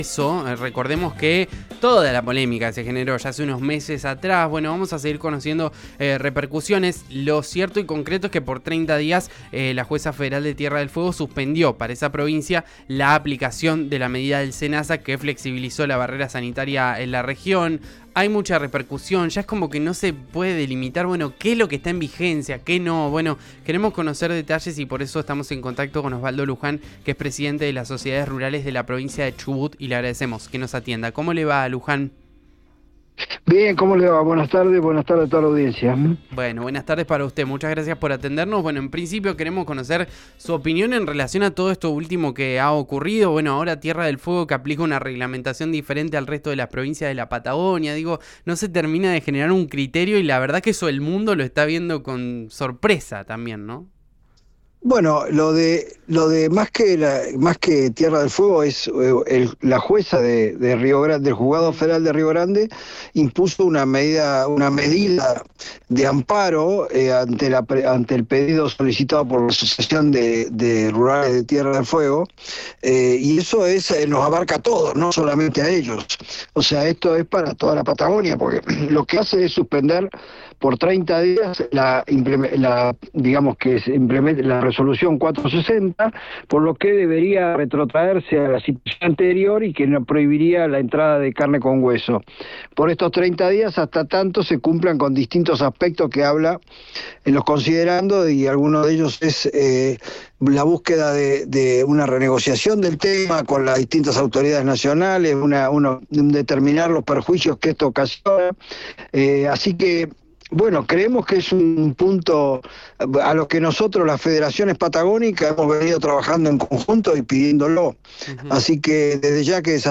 en Rn Radio:
«En el caso de Chubut tuvo repercusión en los reproductores de bovino en pie que se pudo hacer una última exportación pero después ya se cortó», subrayó en diálogo con El Diario del Mediodía.